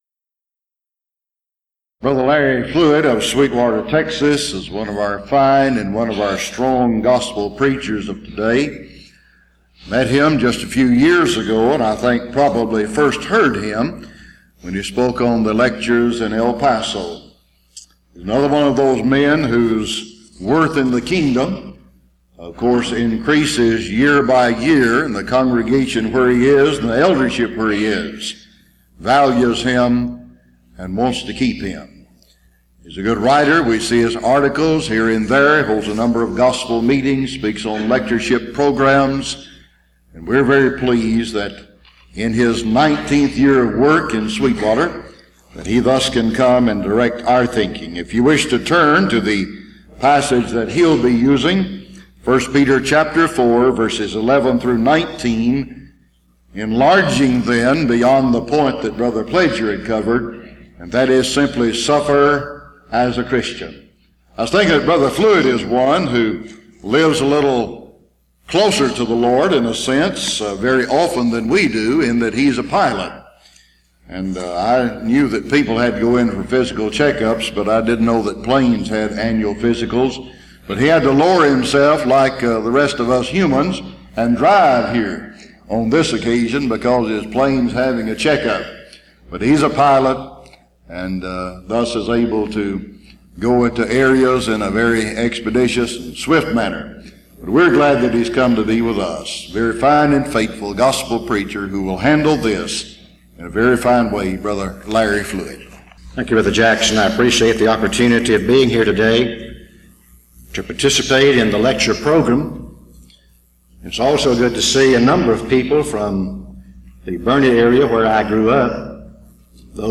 Event: 6th Annual Southwest Lectures
lecture